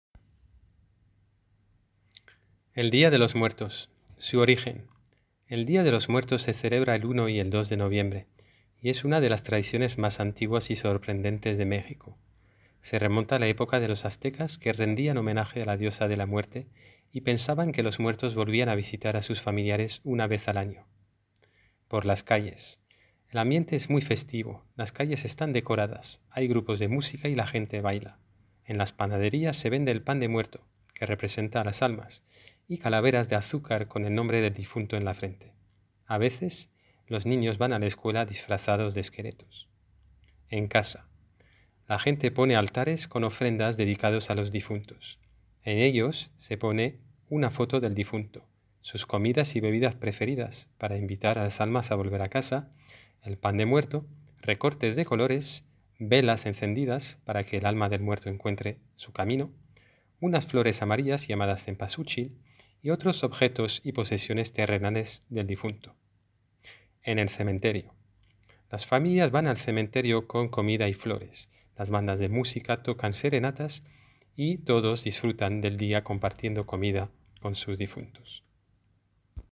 Documentos leídos